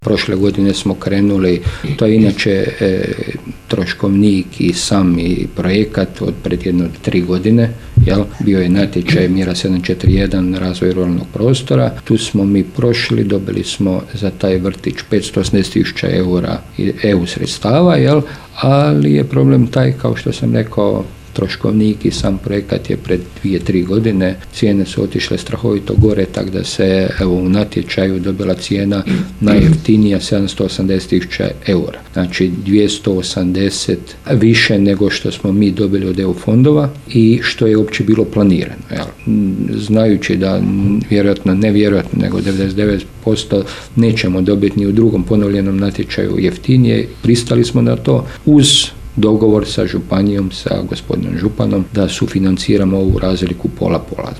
Načelnik Općine Martinska Ves Stjepan Ivoš o (ne)korištenju sredstava iz Fonda solidarnosti EU